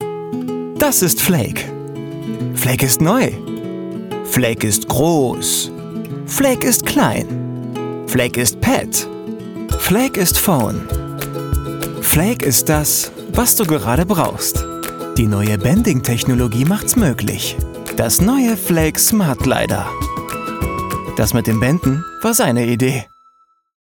hell, fein, zart, sehr variabel
Jung (18-30)
Werbung Rasierer
Commercial (Werbung)